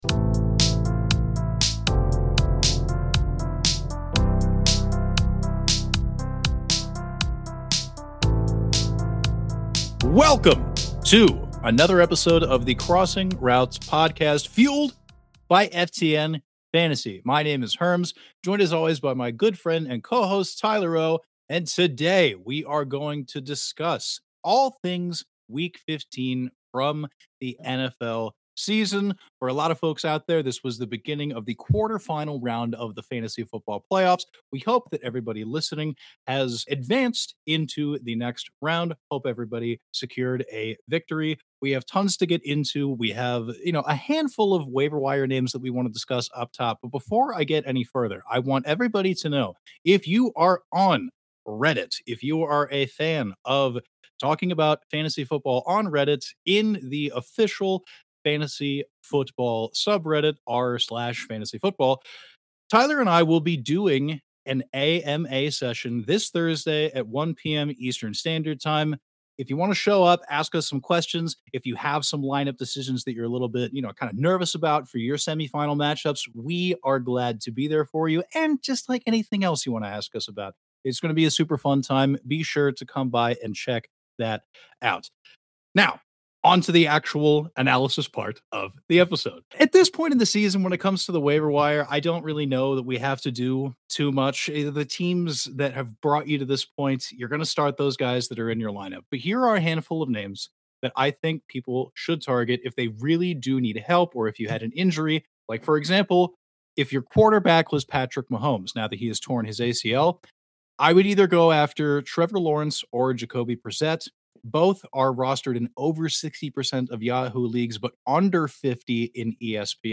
The two long-time industry pals are here to dish on everything from redraft to dynasty, and even some best ball.